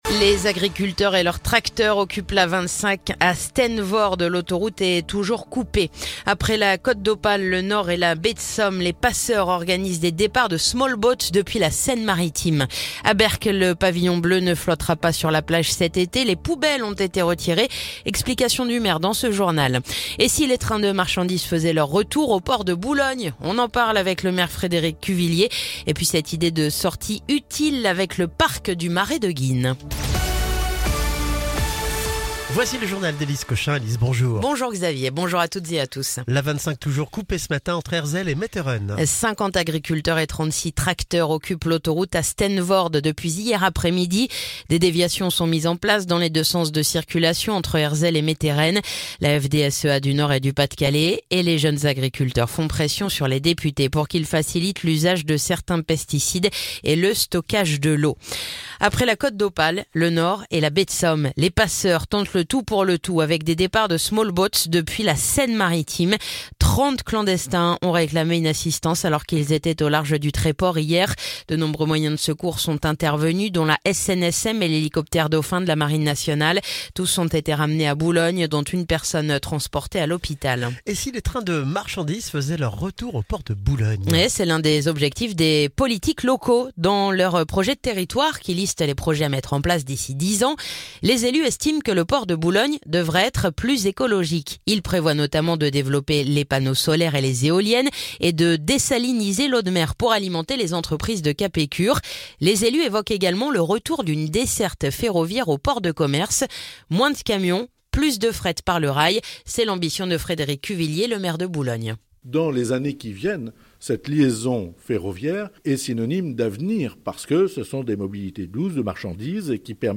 Le journal du mardi 20 mai